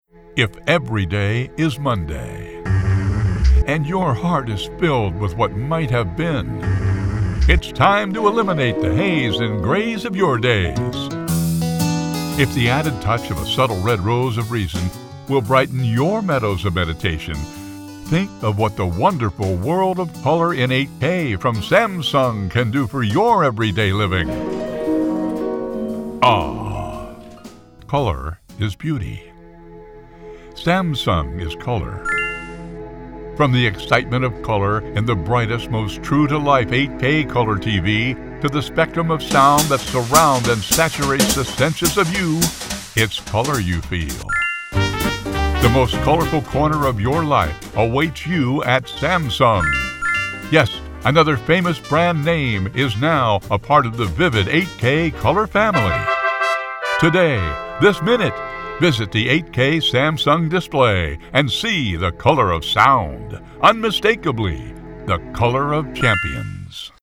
Male
English (North American)
Commercial, Guy next door, Bright, Believable, Confident, Friendly, Warm, Deep, Informative, Authoritative, Happy, Mature
Radio Commercials
Various Commercial Clips